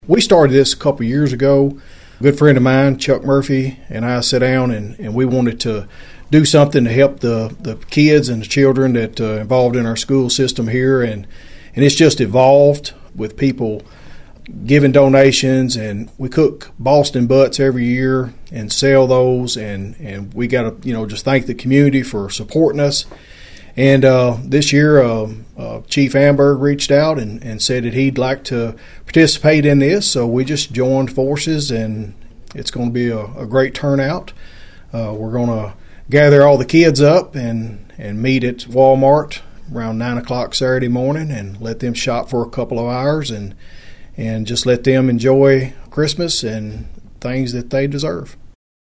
Fulton County Sheriff Chad Parker told Thunderbolt News about the opportunity to help children during the holiday season.(AUDIO)